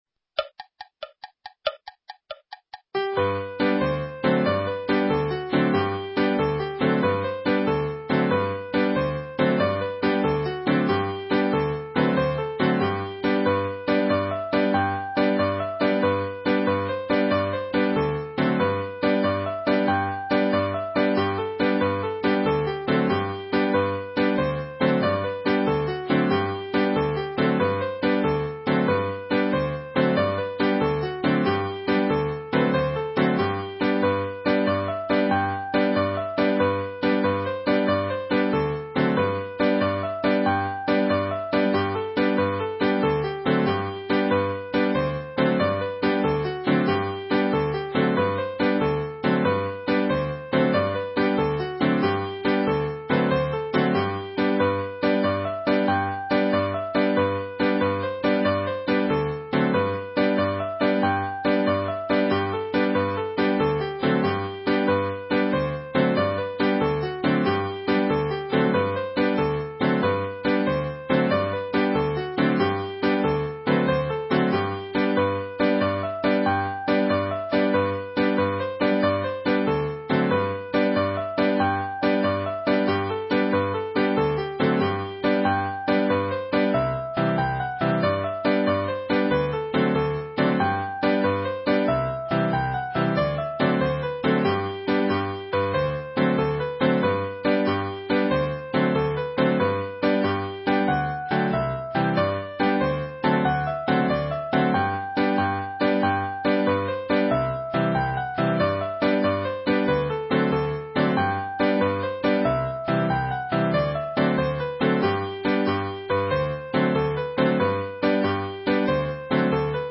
4 Tune Medley
• @@ The Villagers - 4 repeats (NB - Villagers A part is played once through for the introduction)
• @@ Bonny Colne - 2 repeats (NB - Time signature change)